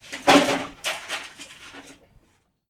falling_tools_00.ogg